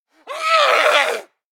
DayZ-Epoch/SQF/dayz_sfx/zombie/chase_4.ogg at 094c127e7bcc96d83e94bc0eafcf9f11b2ac8292